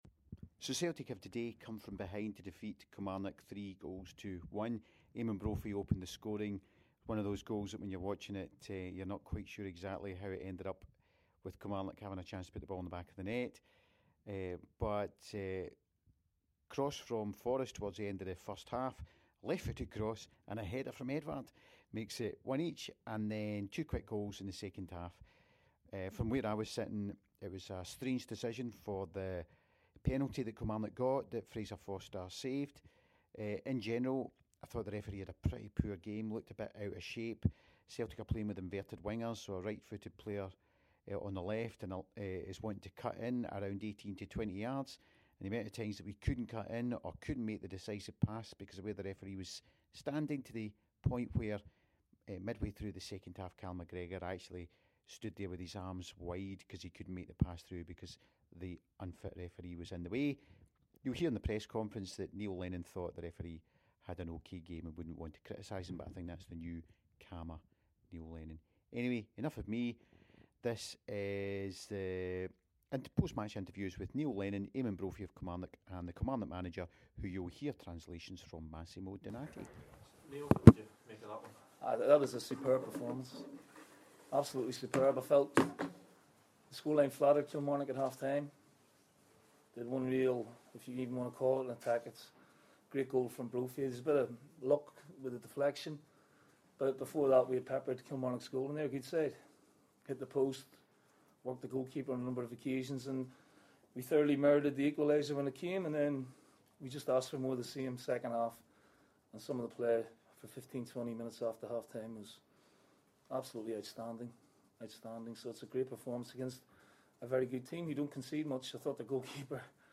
The post match comments from both managers following the victory over Kilmarnock
After the game we got the comments from the Celtic and Kilmarnock managers (with Massimo Donatti doing some translating).